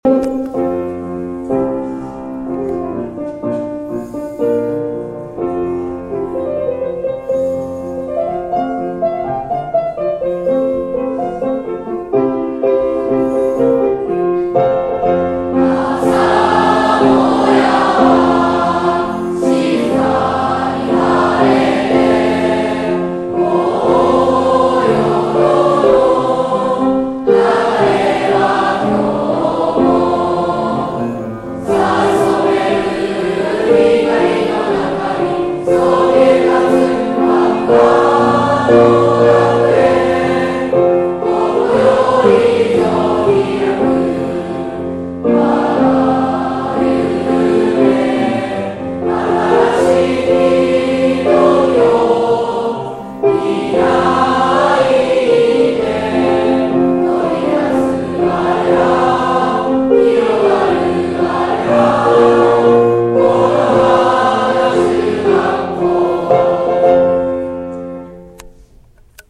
体育館での始業式は、校歌斉唱から始まりました。 申年の幕開けにふさわしい伸びやかな歌声。